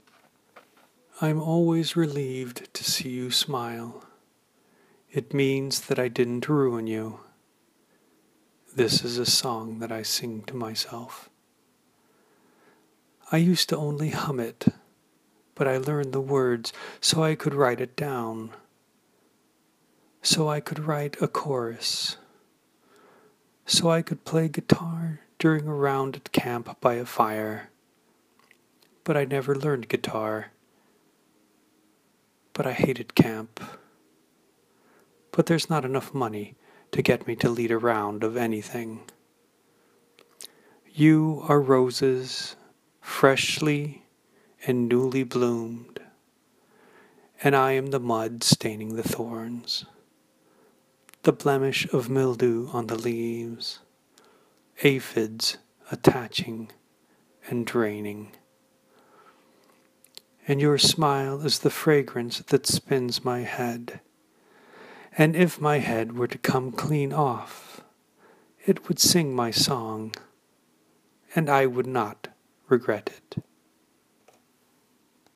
I felt like doing a reading. I think I got my quiet voice on.
It’s a cross of Garrison Keillor and Norman Bates.